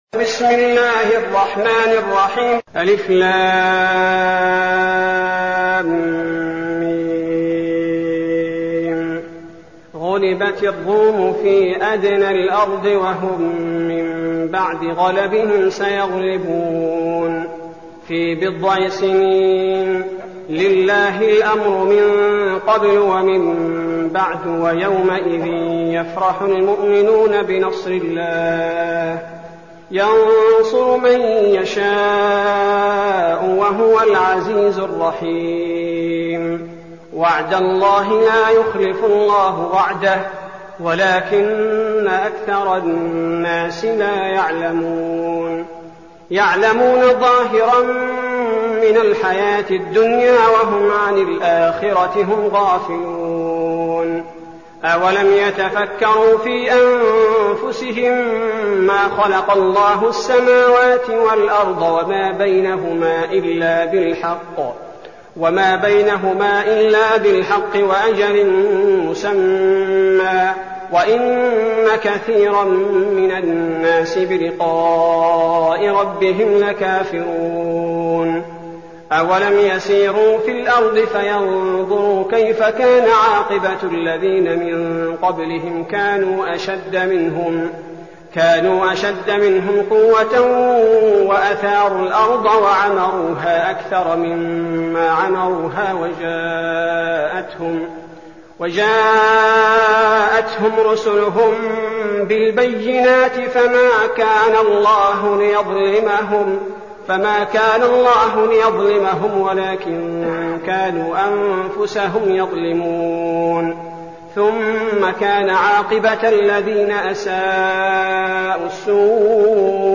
المكان: المسجد النبوي الشيخ: فضيلة الشيخ عبدالباري الثبيتي فضيلة الشيخ عبدالباري الثبيتي الروم